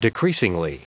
Prononciation du mot decreasingly en anglais (fichier audio)
Prononciation du mot : decreasingly